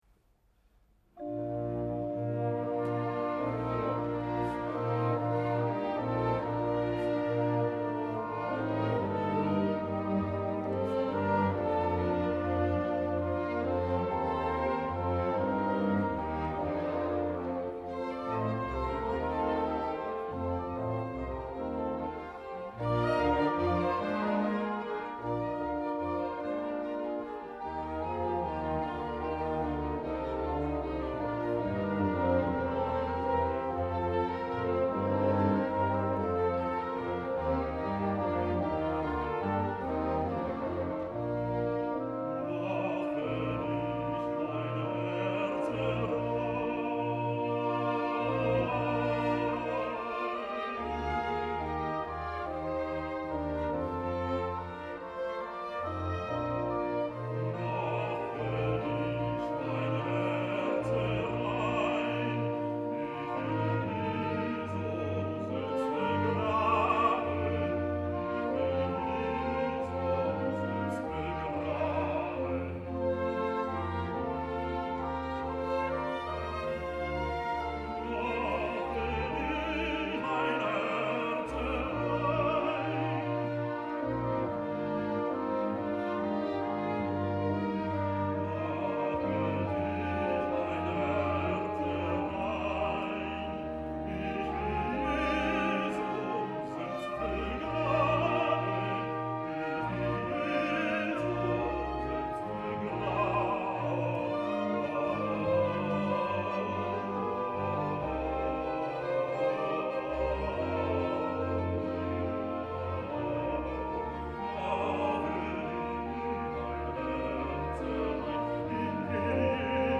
横浜マタイ研究会合唱団
２０１６年４月３０日　神奈川県立音楽堂　　曲目　マタイ受難曲　第二部　J.S.Bach